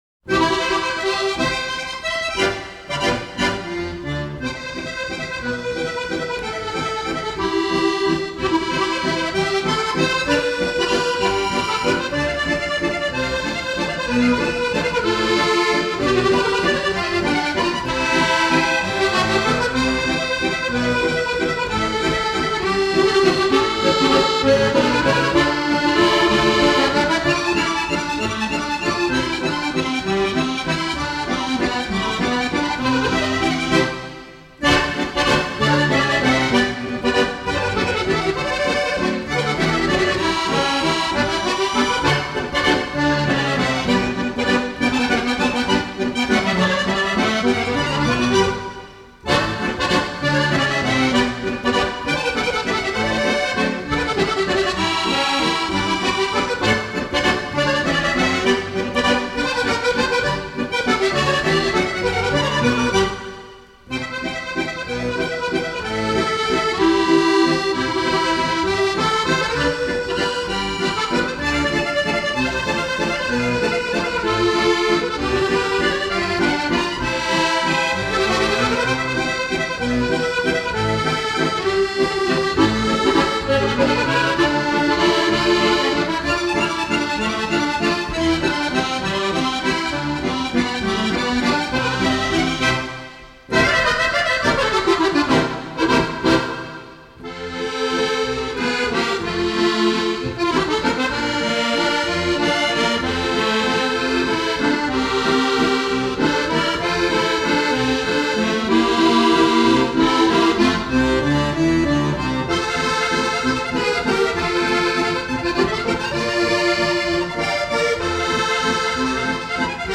Les accordéonistes
Sur les rives du Doubs (Valse)